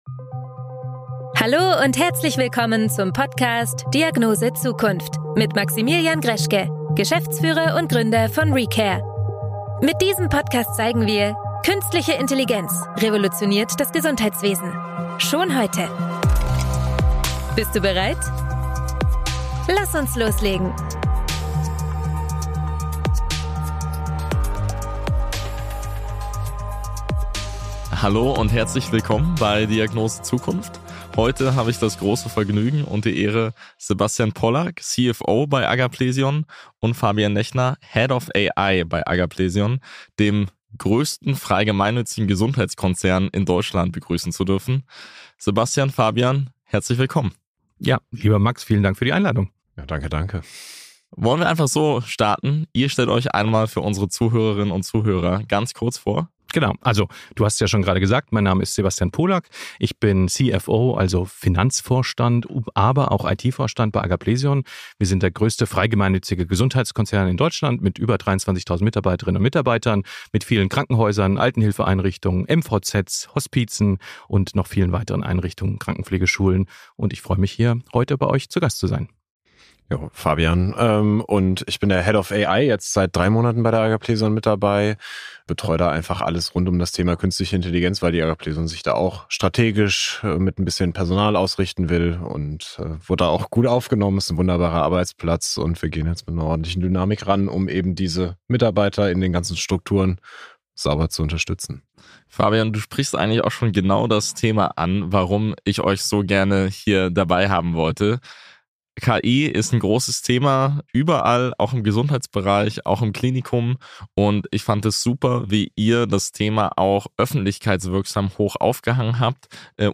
Die Episode gibt dir einen praxisnahen Einblick, wie ein großer Klinikverbund KI strategisch, sicher und wirksam einsetzt – von ersten Quick Wins bis zu Visionen für Robotik und agentische Systeme. Ein Gespräch über Chancen, Stolpersteine und die Zukunft der Gesundheitsversorgung.